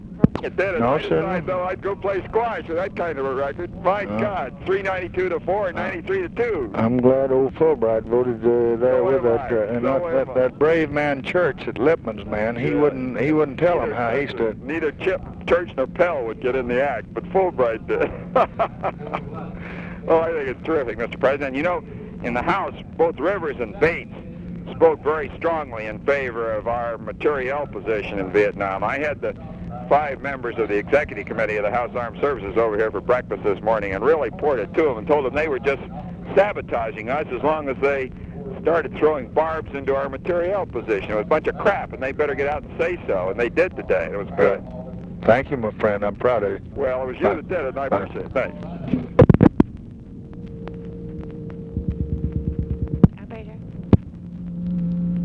Conversation with ROBERT MCNAMARA, March 1, 1966
Secret White House Tapes